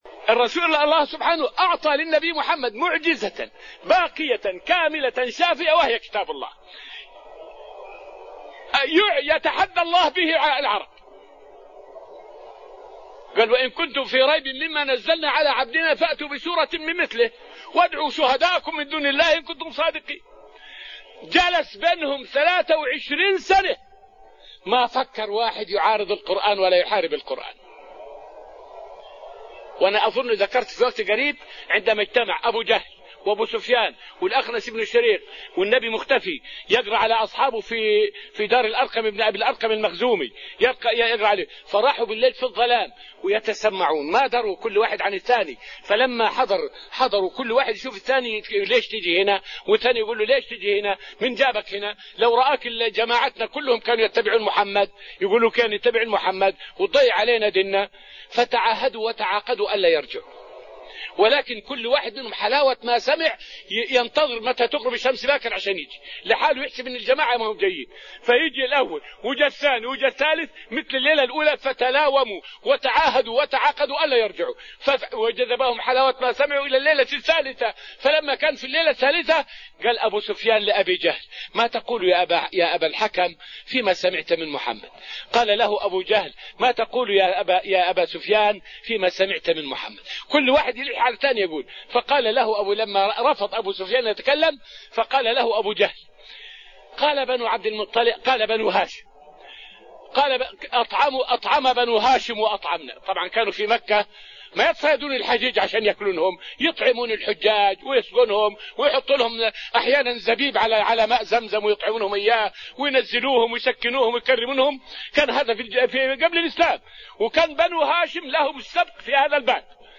فائدة من الدرس التاسع من دروس تفسير سورة الذاريات والتي ألقيت في المسجد النبوي الشريف حول سر التعبير بــ "من"، بدل "في" في قوله: {فويل للذين كفروا من يومهم}.